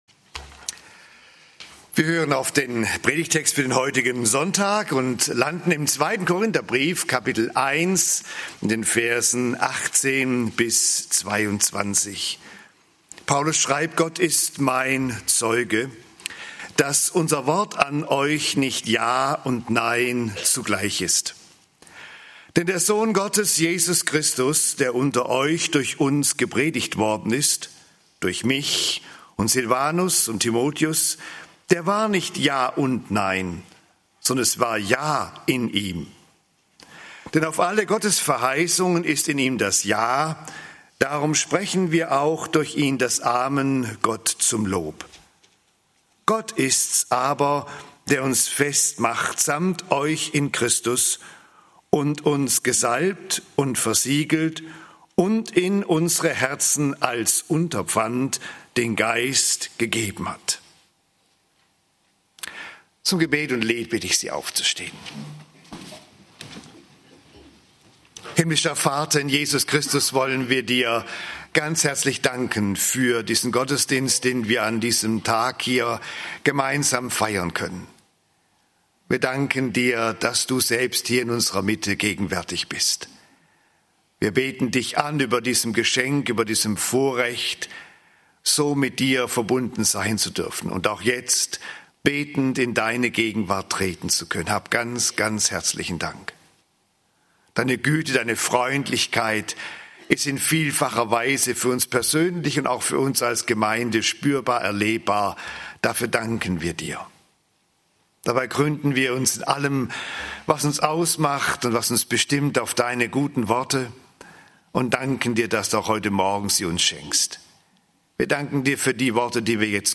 Jesus - Gottes Ja! (2. Kor. 1, 18-22) - Gottesdienst